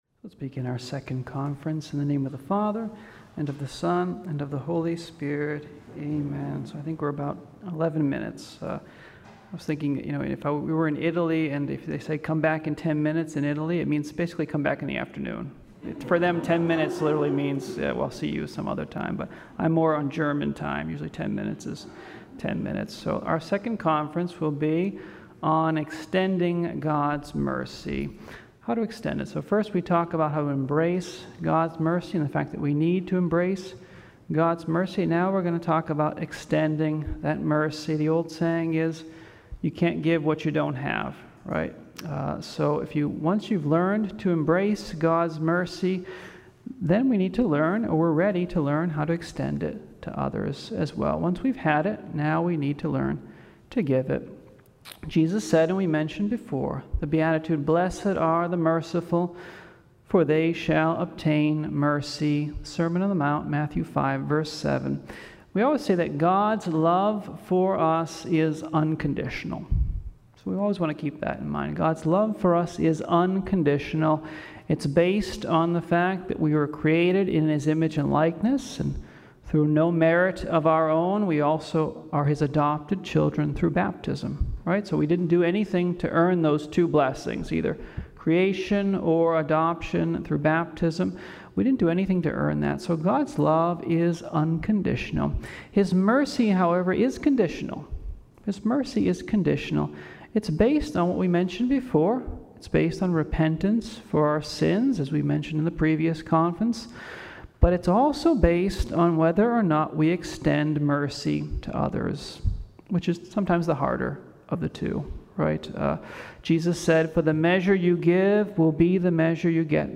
the second of two talks for the Divine Mercy Day of Reflection 2021 explains how we need to extend to others the mercy of God that He extended to us.